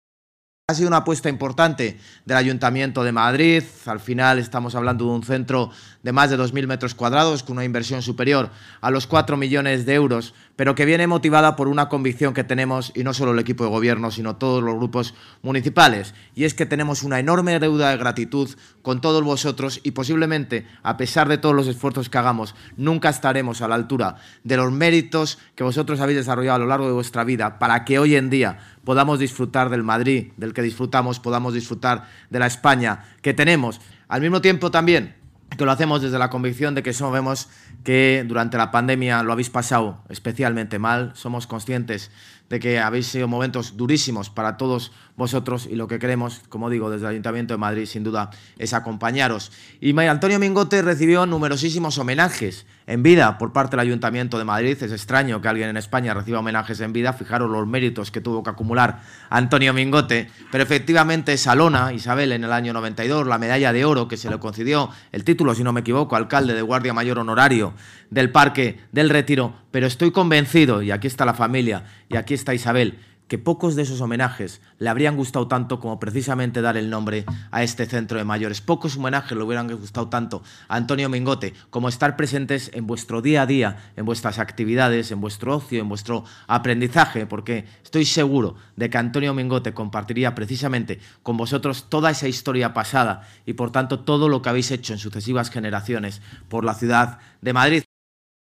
JLMartinezAlmeida-CentroMayoresAntonioMingote-07-02.mp3